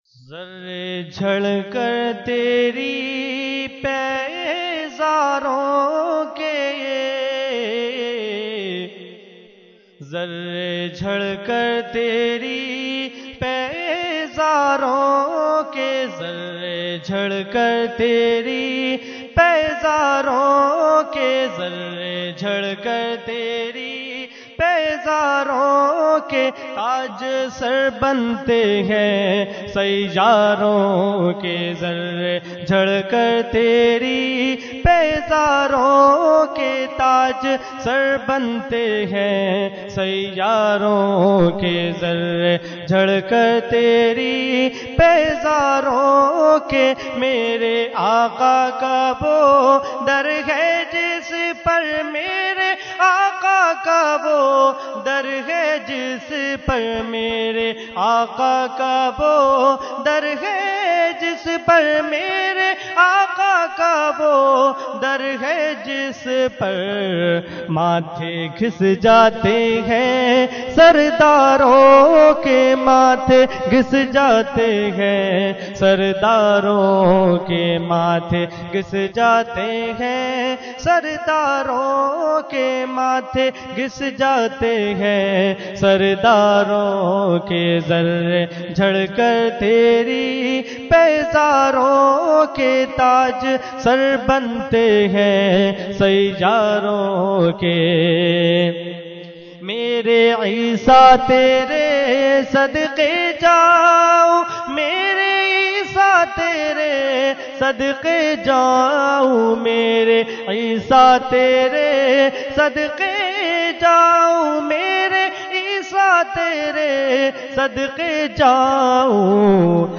Category : Naat | Language : UrduEvent : Khatmul Quran 2014